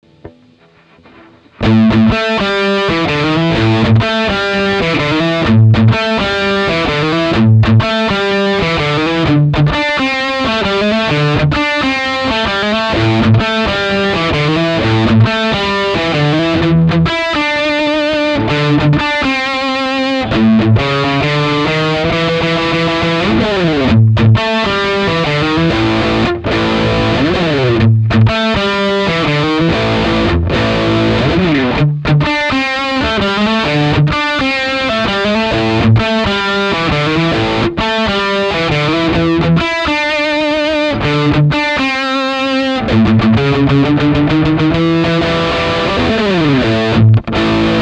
Китара -> Lee Jackson GP1000 Tube Preamp ->Ultra-G (Speaker Simulation ON) -> Mixer -> sound card
Ламповият преамп е роден през 80-те години и затова има и такъв звук - няма особено много гейн, но мен страшно си ме кефи и дори намирам звука за плътен.